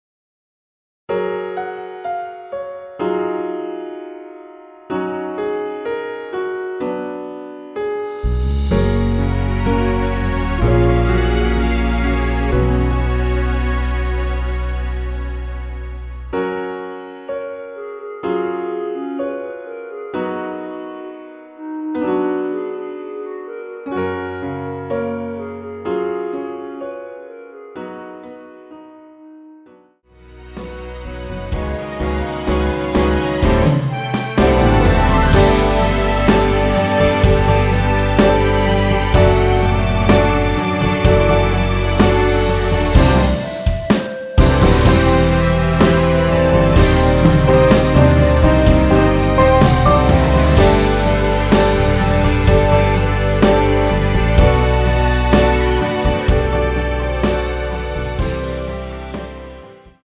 남자키 멜로디 포함된 MR 입니다.(미리듣기 참조)
F#
앞부분30초, 뒷부분30초씩 편집해서 올려 드리고 있습니다.
(멜로디 MR)은 가이드 멜로디가 포함된 MR 입니다.